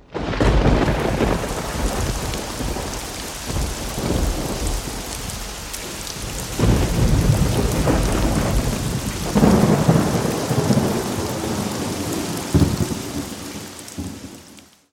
weather_alarm_thunderstorm3.ogg